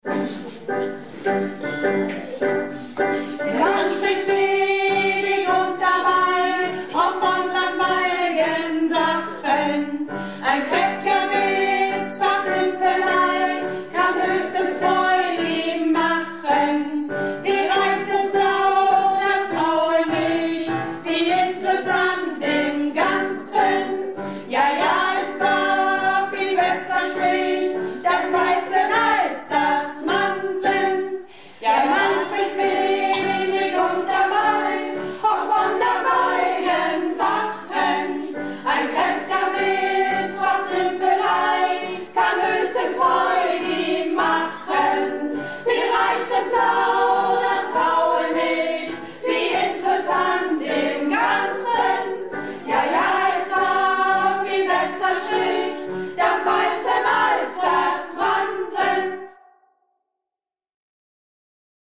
Alt und Sopran